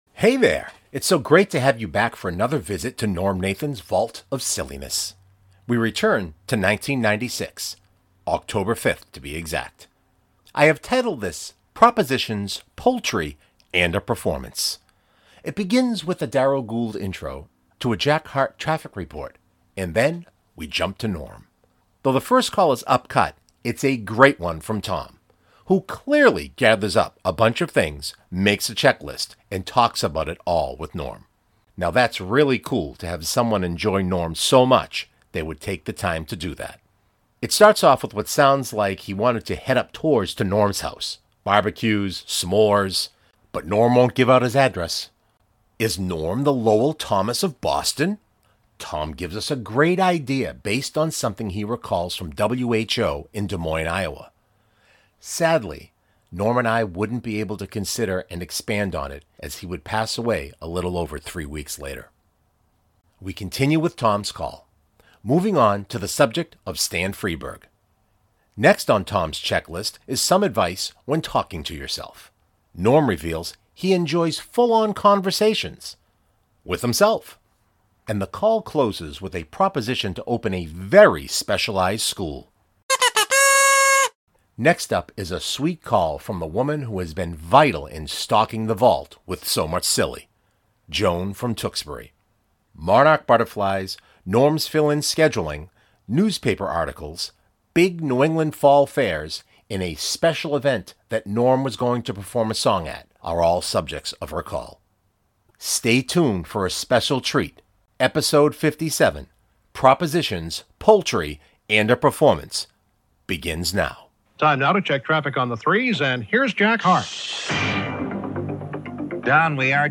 Though the first call is upcut